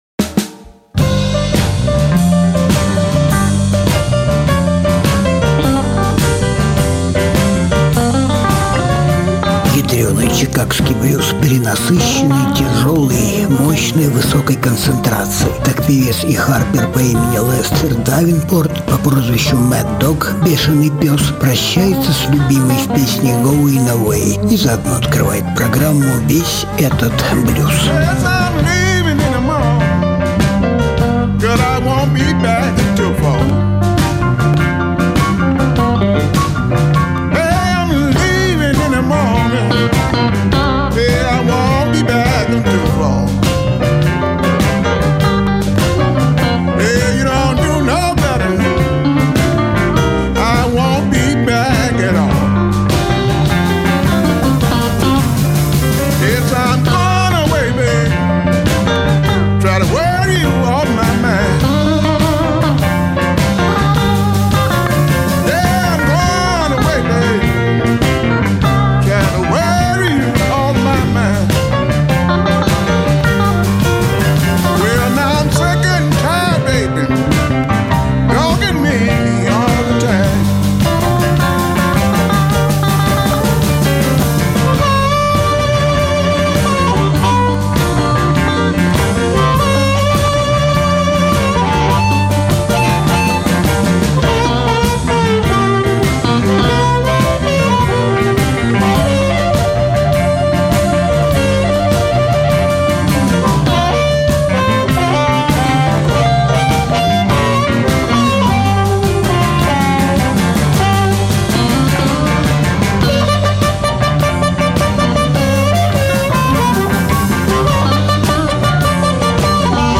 чикагский блюзовый гитарист и певец.
гитаристка и певица
Жанр: Блюзы и блюзики
певец и исполнитель на губной гармонике